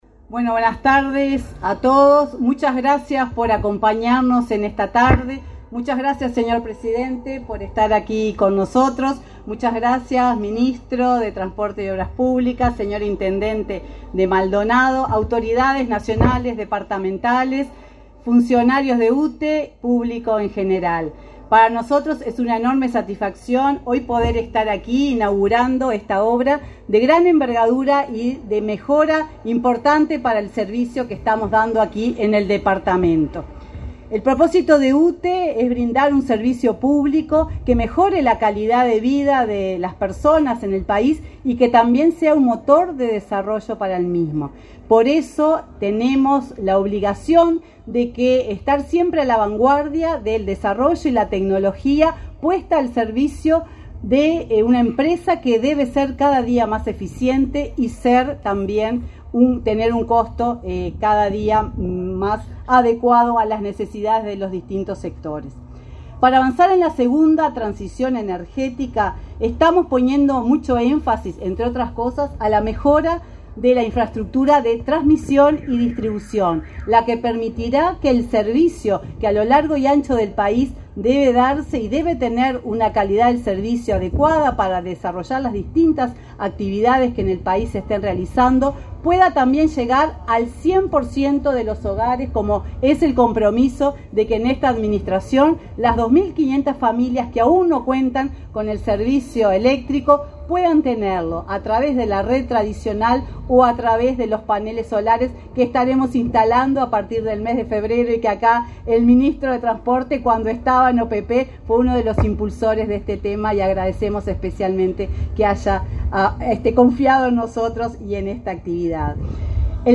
Conferencia de prensa por la inauguración de la estación de energía eléctrica en Punta del Este
El presidente de la República, Luis Lacalle Pou, participó en la inauguración de la estación de transformación y distribución de energía eléctrica de